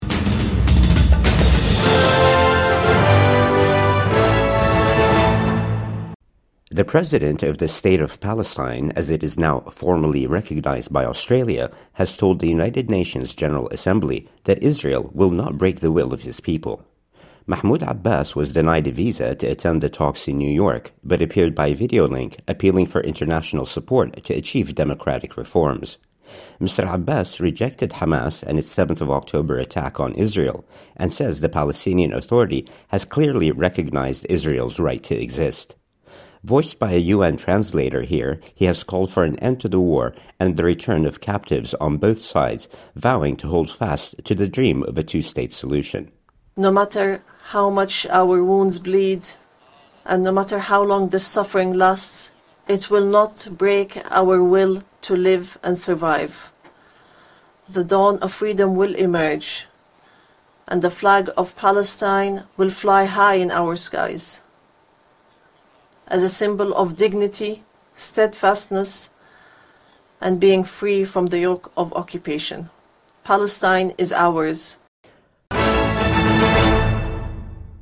The President of the State of Palestine addresses the UN General Assembly